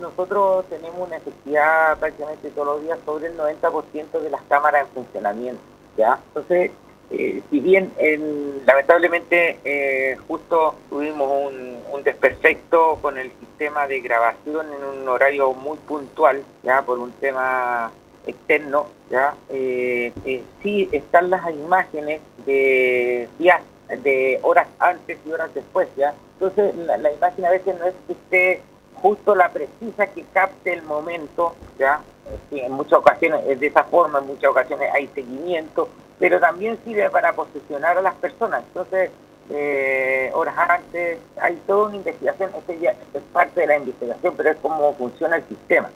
En una conversación con Radio Paulina, Soria aclaró que, si bien hubo un desperfecto con el sistema de grabación en uno de los homicidios, el sistema de cámaras continúa siendo uno de los más amplios y efectivos en Chile.